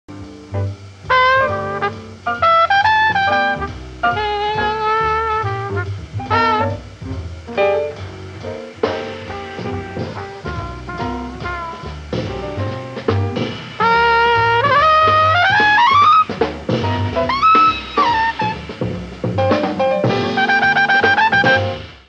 LIVE AT TEATRO DELL' ARTE, MILAN, ITALY
SOUNDBOARD RECORDING